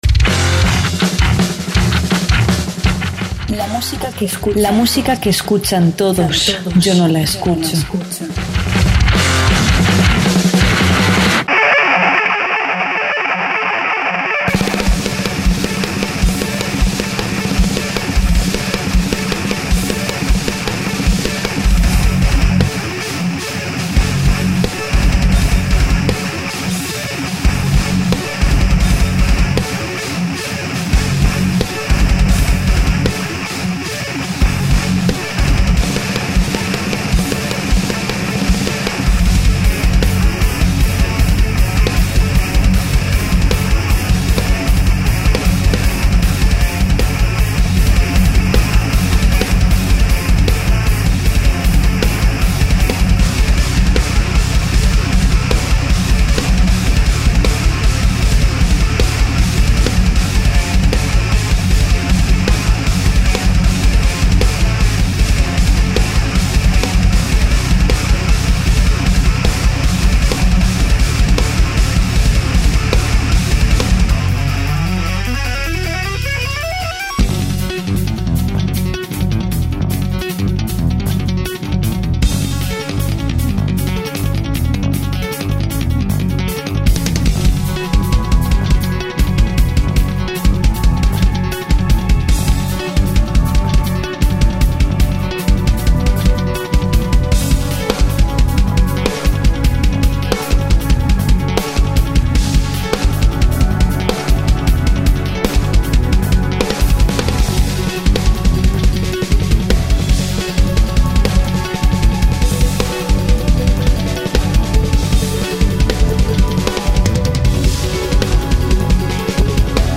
Además, escucharemos algunos temas de este trabajo.